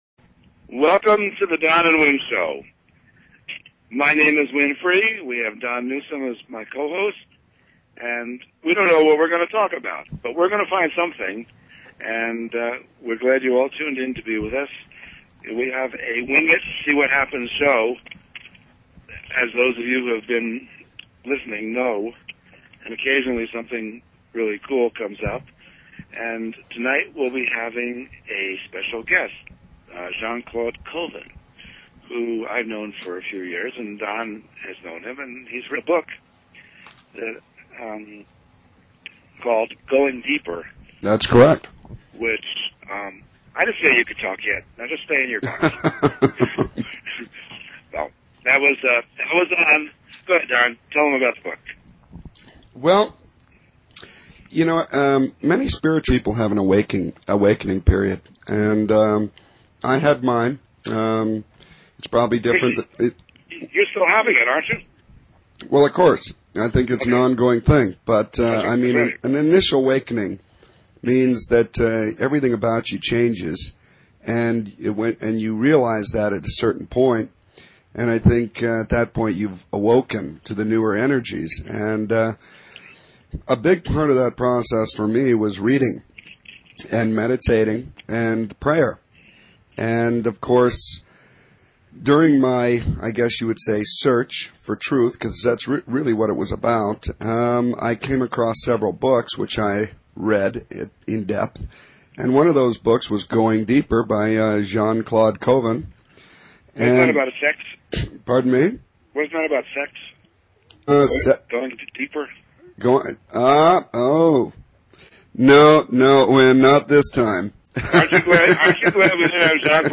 Talk Show Episode, Audio Podcast
Interview